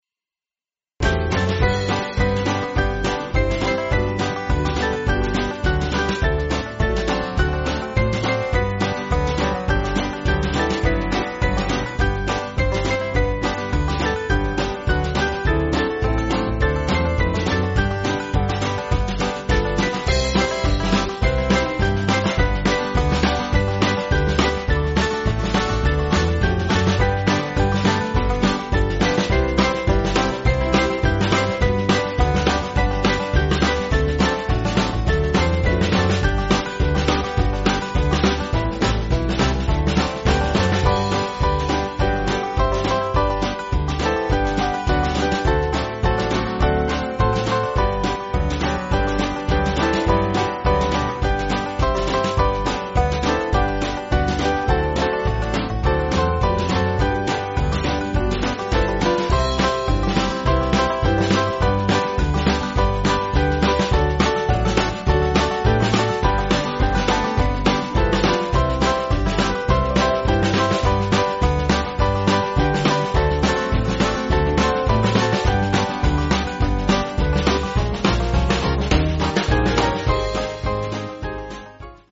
Small Band
(CM)   3/G-Ab
Country/Gospel